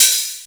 HH OPEN03.wav